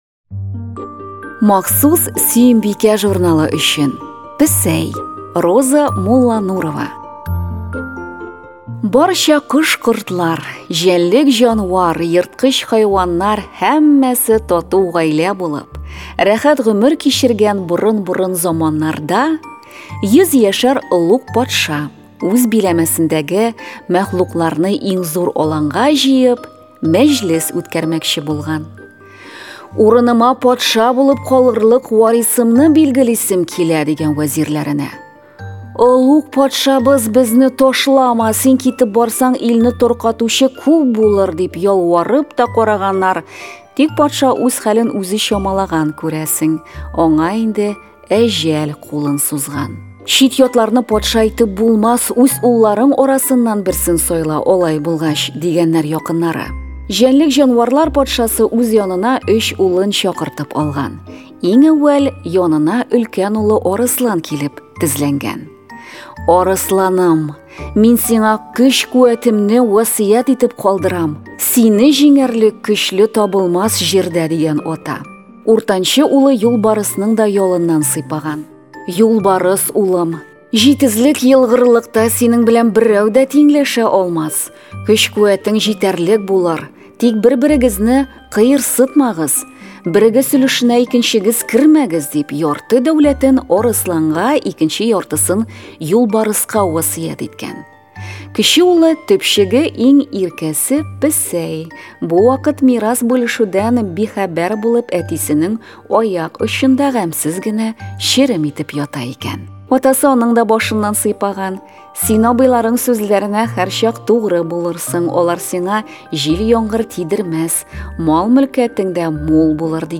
Татар халык әкияте